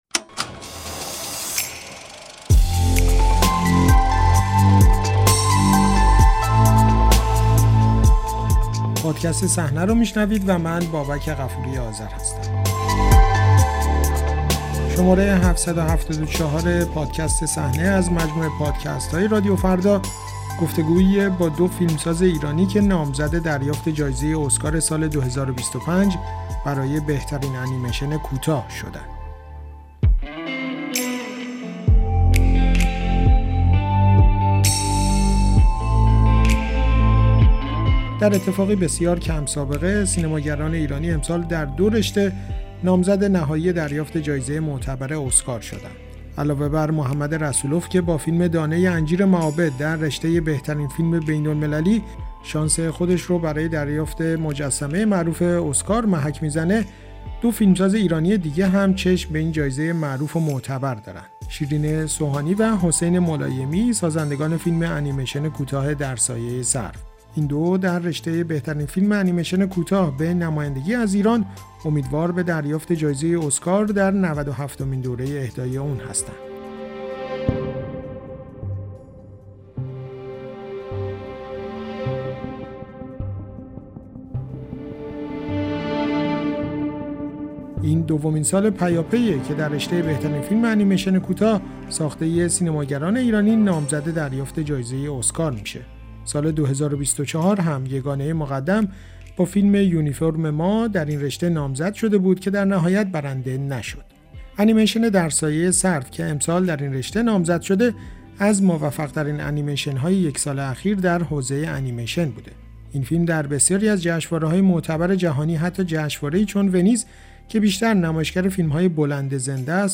گفت‌وگو با دو نامزد ایرانی اسکار ۲۰۲۵ برای بهترین انیمیشن کوتاه